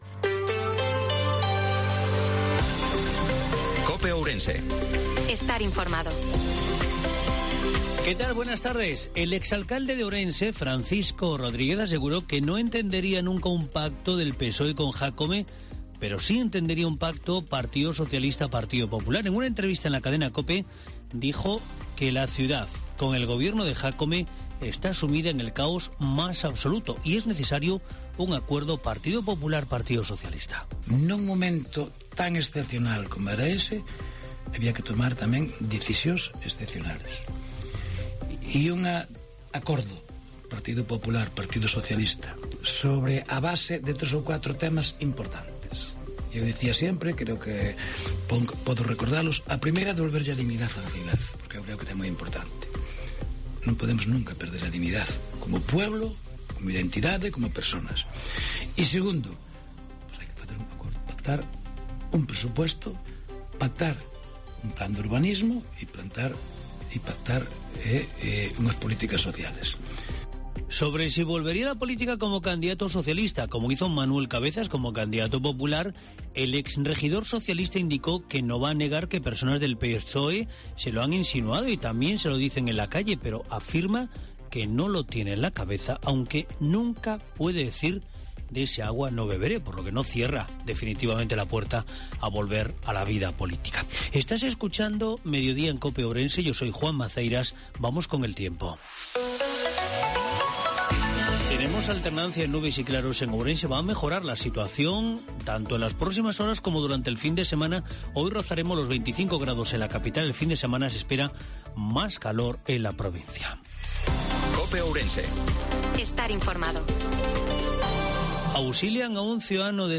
INFORMATIVO MEDIODIA COPE OURENSE-30/09/2022